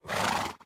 horse_idle2.ogg